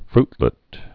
(frtlĭt)